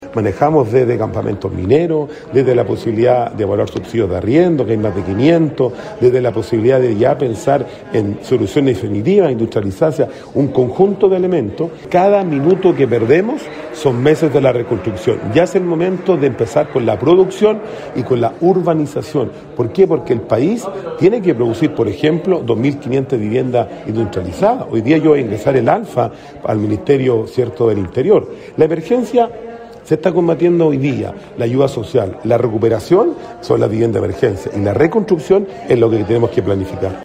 En tiempos de resiliencia y reconstrucción: Desafíos del desarrollo urbano y rural para el Chile del 2050 es el título del seminario organizado por la Asociación Chilena de Municipalidades y que se desarrollará en la Universidad del Bío-Bío hasta este viernes.